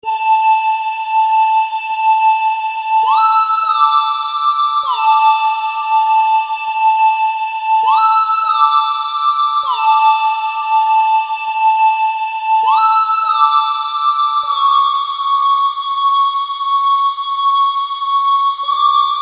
描述：用于休闲/环境音乐的旧式长笛
Tag: 100 bpm Ambient Loops Flute Loops 3.23 MB wav Key : Unknown